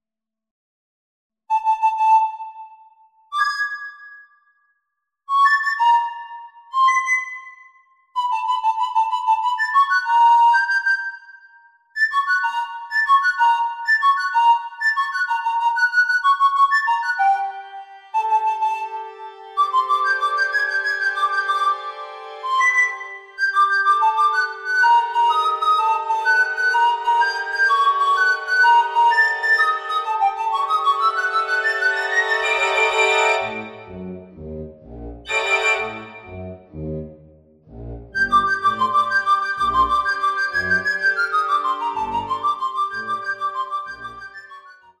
recorders, organ
(Audio generated by Sibelius/NotePerformer)